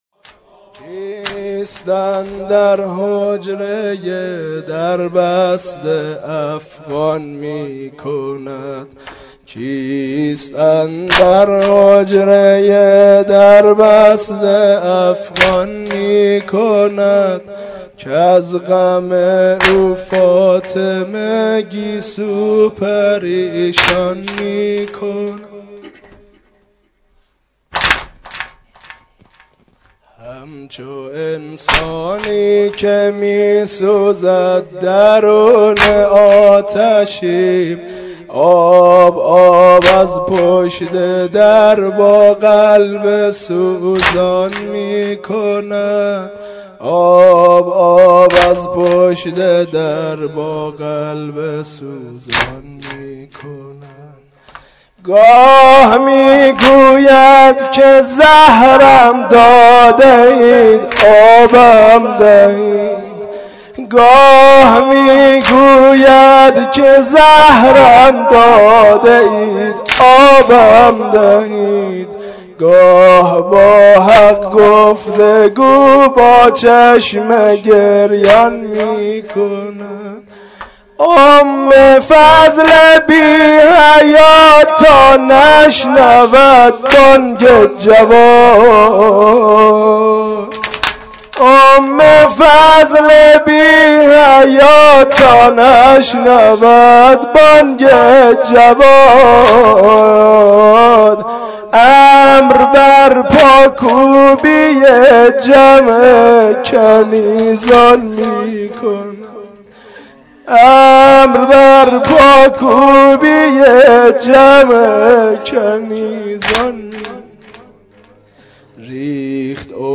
مراسم شب شهادت حضرت جواد الائمه (ع)؛ قسمت سوم